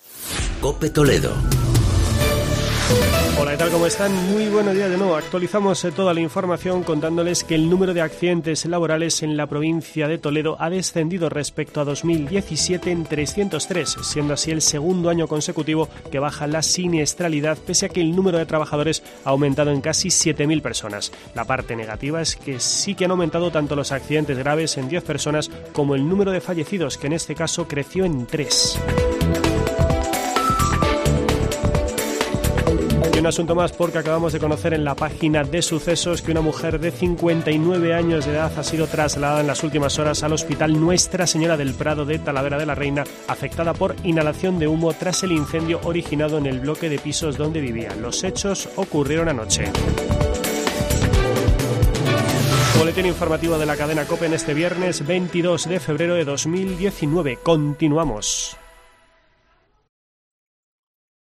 Boletín informativo de la Cadena COPE.